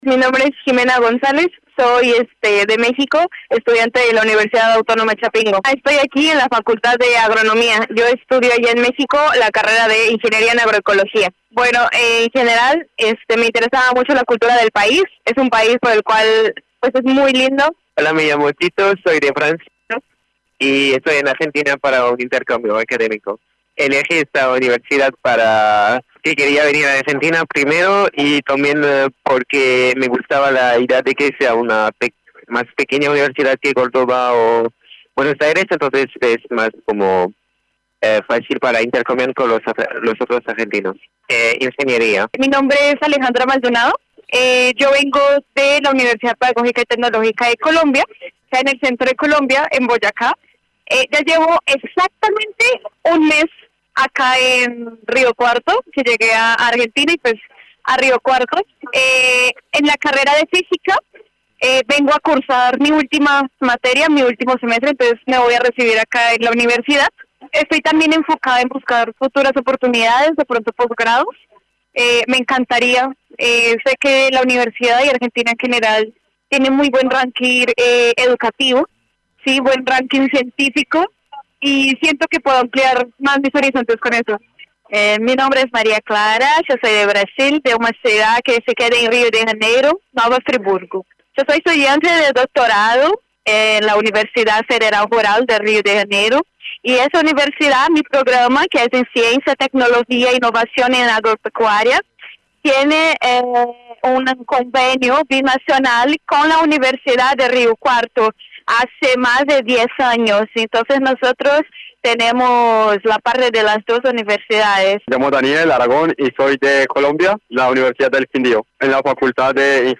Testimonios-estudiantes-EDITADA.mp3